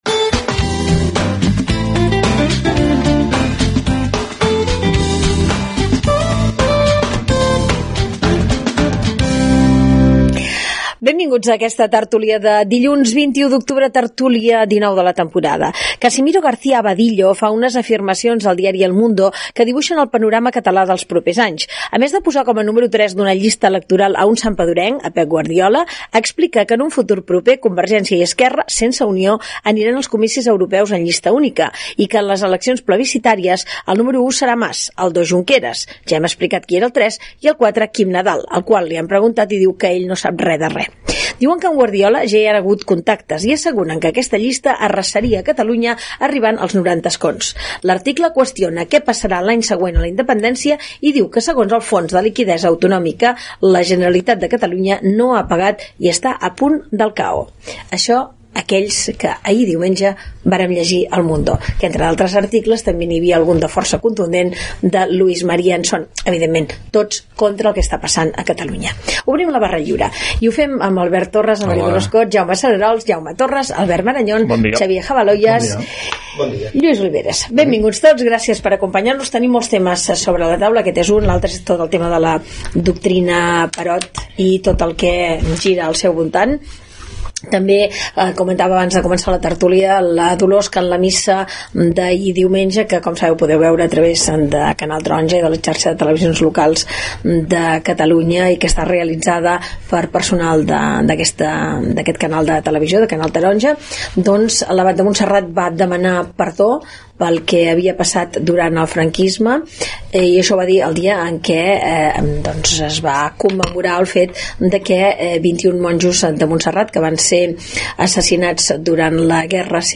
Barra Lliure: tertúlia - Ràdio Manresa, 2013
Àudios: arxiu sonor de Ràdio Manresa